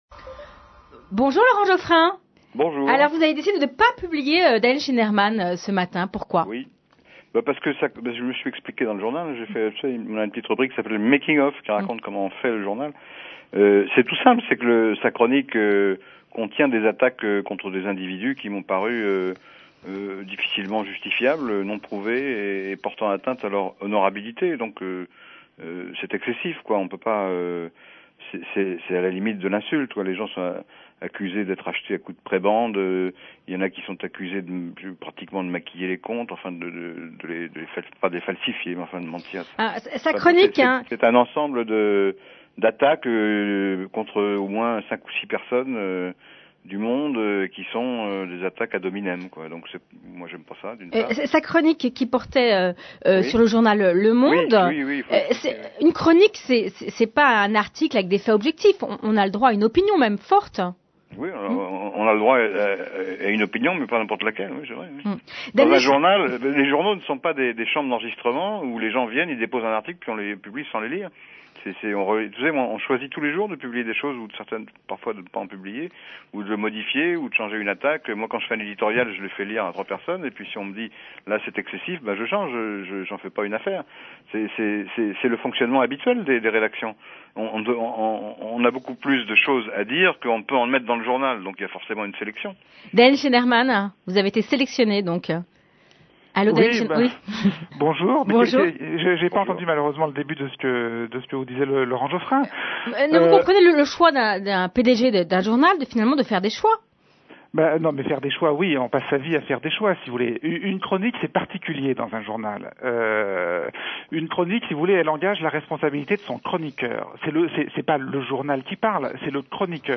- Laurent Joffrin et Daniel Schneidermann répondent à Colombe Schneck dans l’émission « J’ai mes sources », France inter, 1er février 2008.